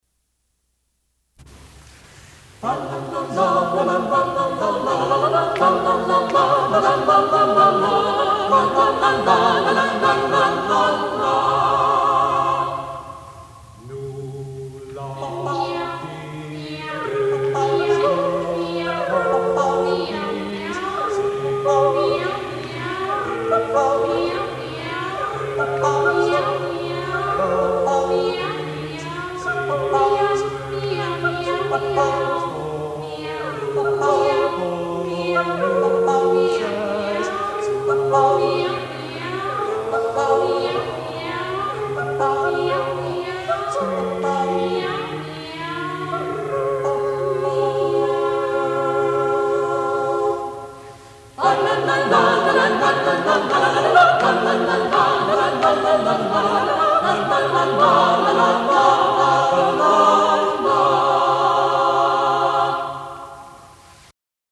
“Un cane, un cucco, un gatto e un chiù per spasso fan contraponto a mente sopra un basso” (A dog, a cuckoo, a cat and an owl make merry with counterpoint on a ground-bass.)